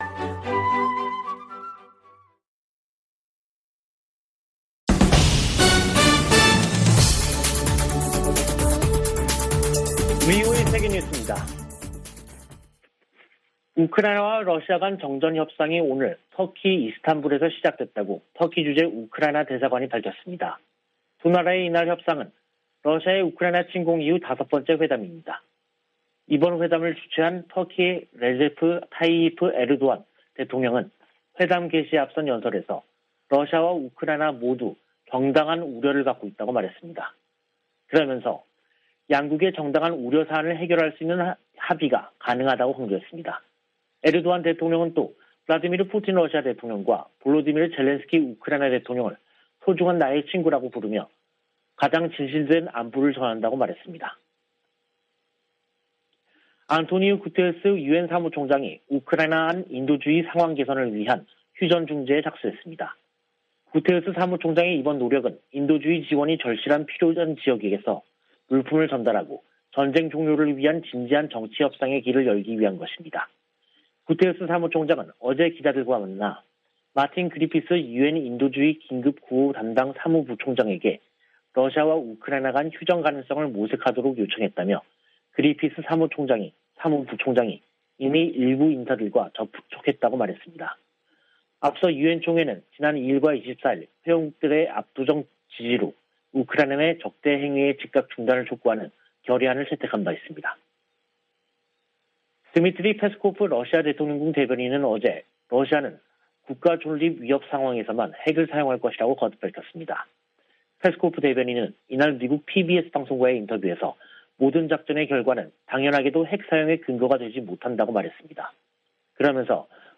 VOA 한국어 간판 뉴스 프로그램 '뉴스 투데이', 2022년 3월 29일 2부 방송입니다. 한국 국방부는 북한이 지난 24일 발사한 ICBM이 '화성-17형'이 아닌 '화성-15형'인 것으로 판단했습니다. 한국 함동참모본부는 미한일 세 나라가 하와이에서 합참의장회의를 개최한다고 밝혔습니다. 미국이 새 유엔 안보리 대북제재 결의안 채택을 추진하고 있지만, 실현 가능성이 낮다는 전망이 지배적입니다.